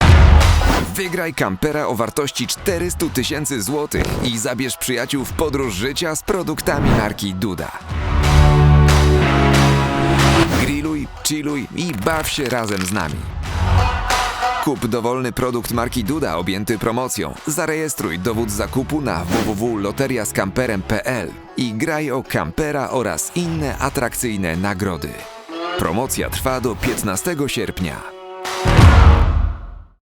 Soy hablante nativo de Polonia y llevo varios años grabando en mi propio estudio.
Profesional
Fresco
Joven